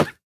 Minecraft Version Minecraft Version latest Latest Release | Latest Snapshot latest / assets / minecraft / sounds / mob / armadillo / hurt_reduced4.ogg Compare With Compare With Latest Release | Latest Snapshot
hurt_reduced4.ogg